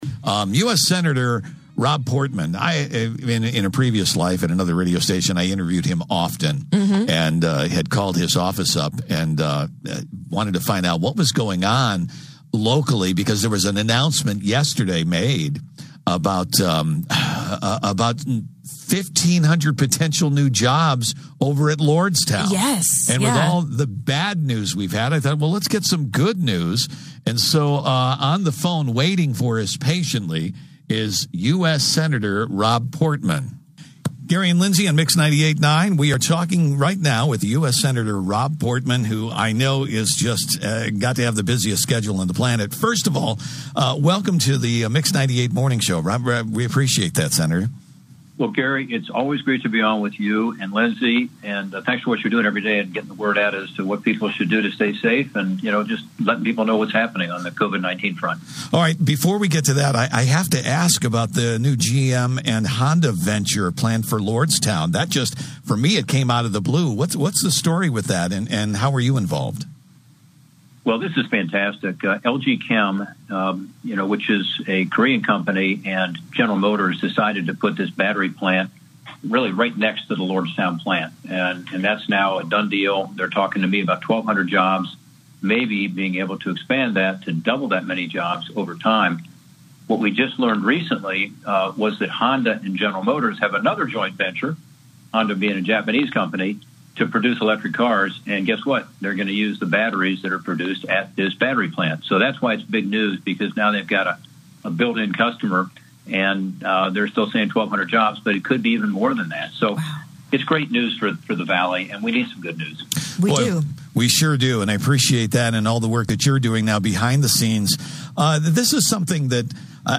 Among the topics discussed in the interview: